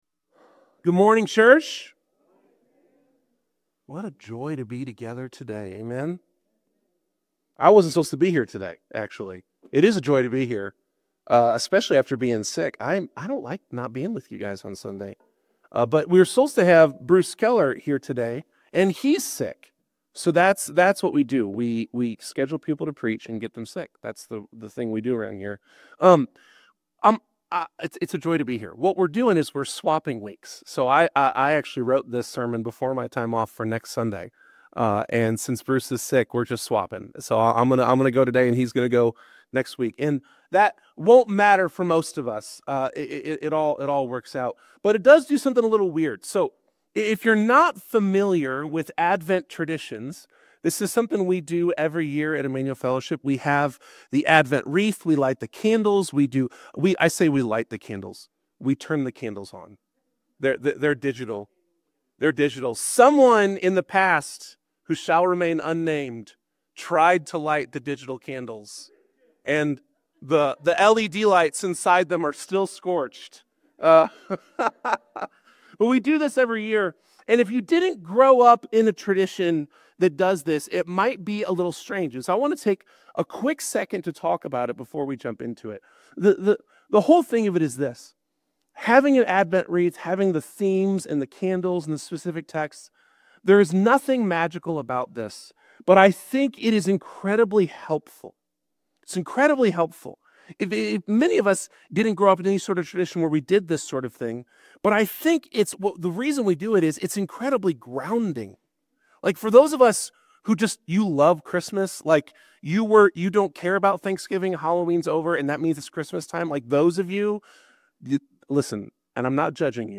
Join us for an inspiring sermon on the theme of faith, as we delve into the story of Joseph from the Gospel of Matthew. Discover how Joseph's unwavering trust in God's plan, despite personal sacrifice and societal pressure, serves as a powerful example of living a life of faith.